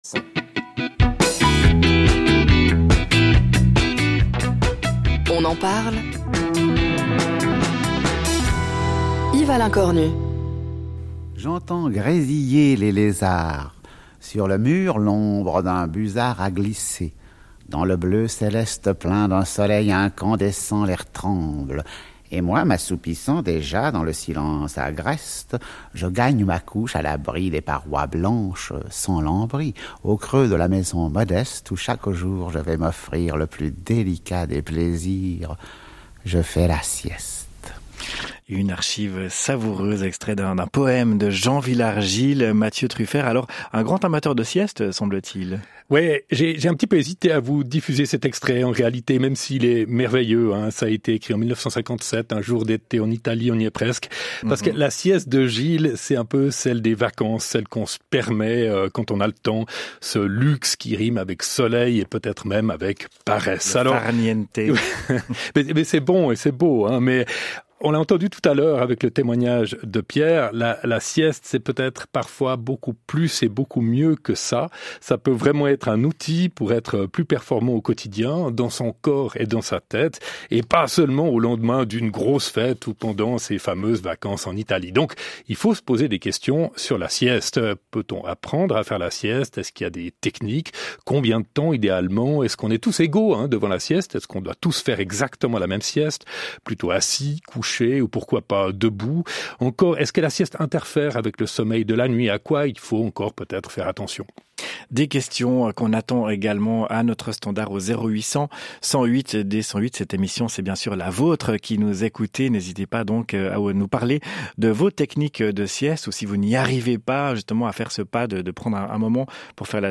Emission "On en parle"